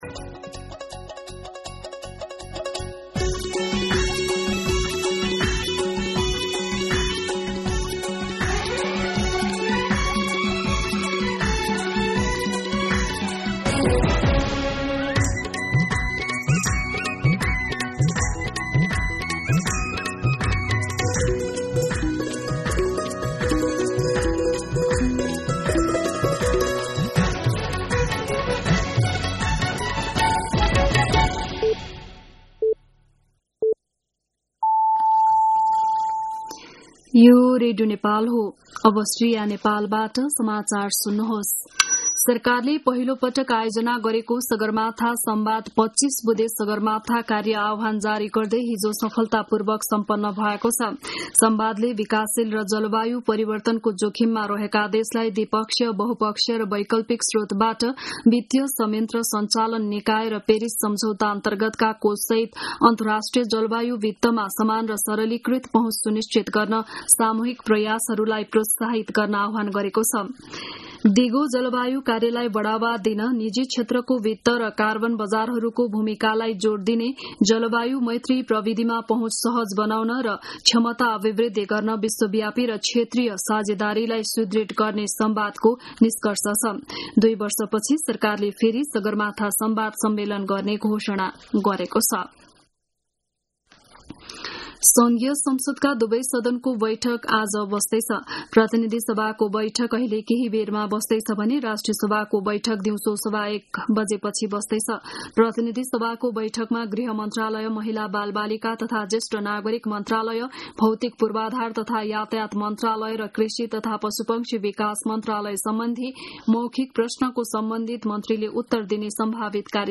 बिहान ११ बजेको नेपाली समाचार : ५ जेठ , २०८२